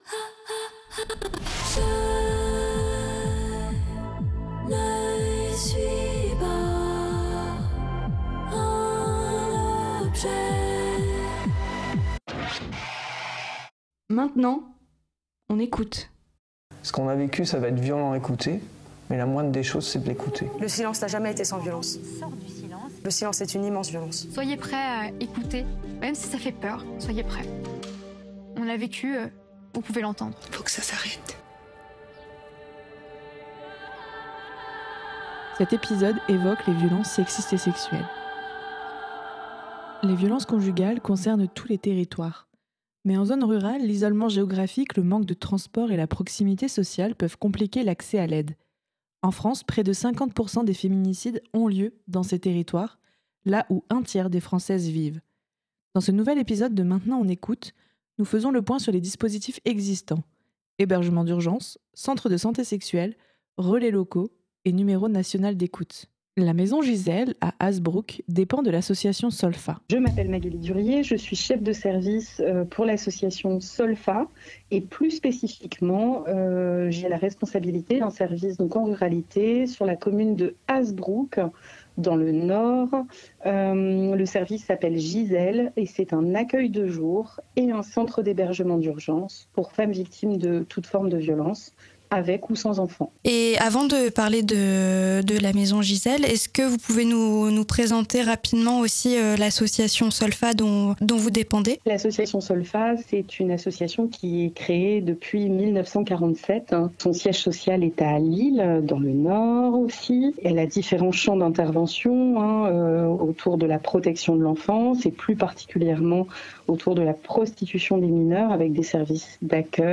Cette émission donne la parole aux victimes de VSS ou aux personnes agissant contre ces violences.